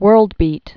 (wûrldbēt)